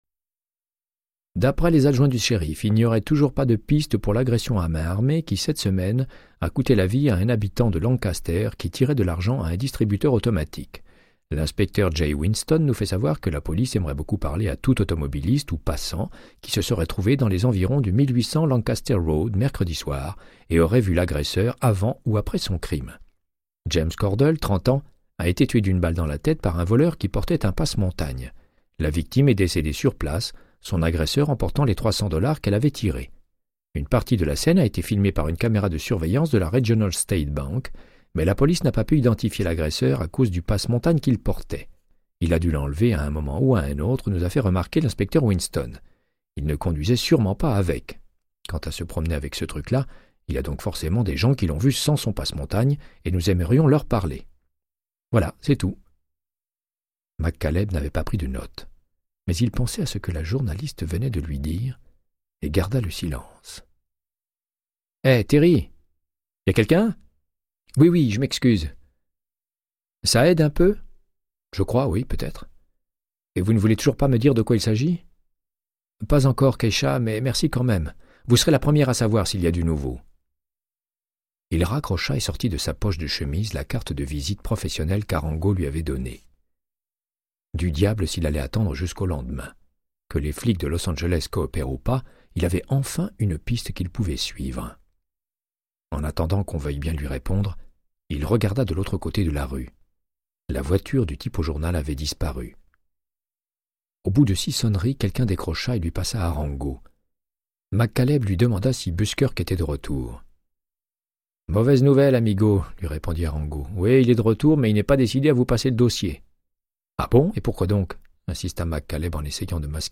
Audiobook = Créance de sang, de Michael Connellly - 23